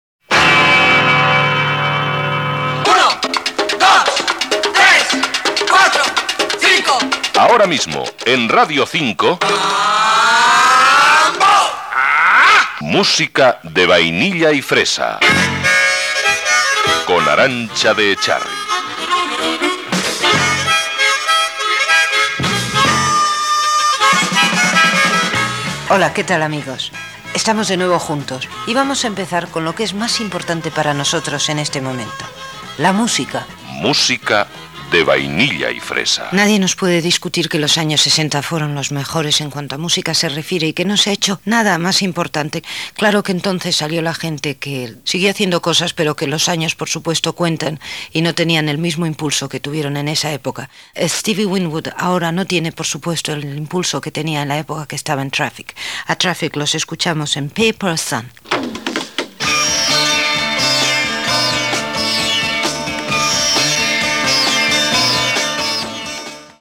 Careta del programa (en veu de Constantino Romero), presentació del programa i tema musical
Musical